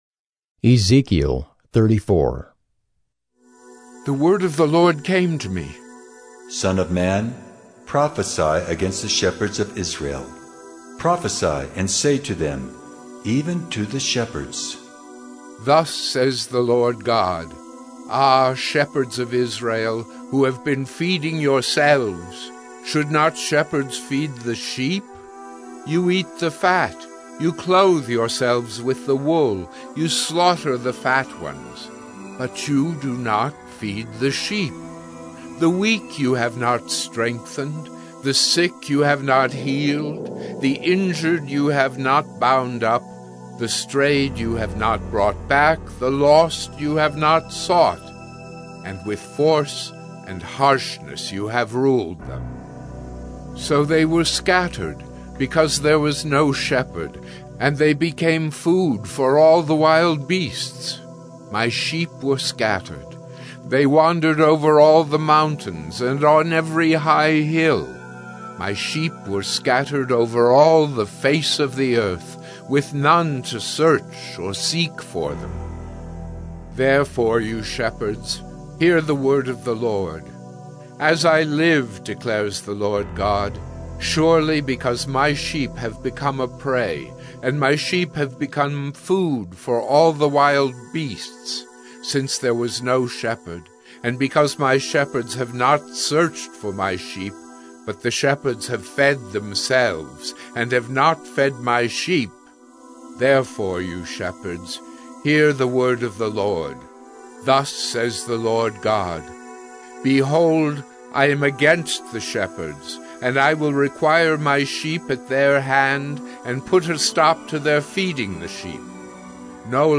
“Listening to God” Bible Reading & Devotion: Oct. 5, 2021 – Ezekiel 034